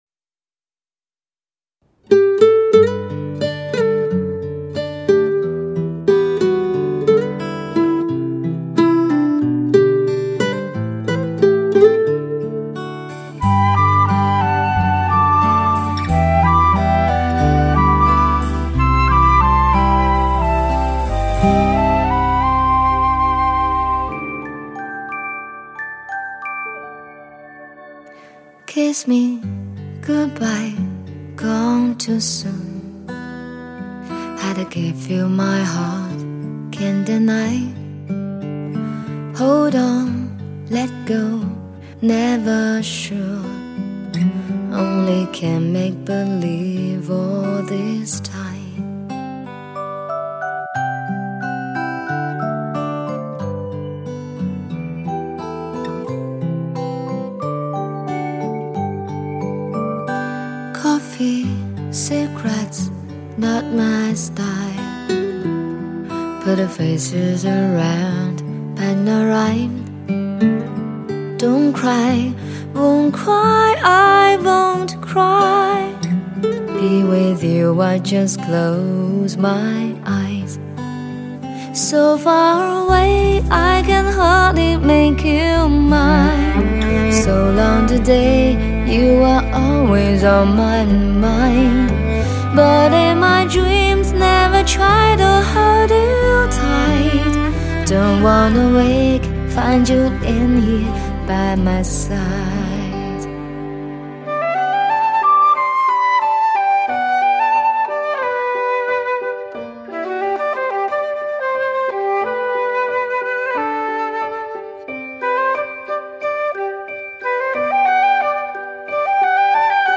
（国语流行 英文演绎）
音乐风格: 流行
12首真挚代表作 采用HI-FI级典范录音一次性精彩呈现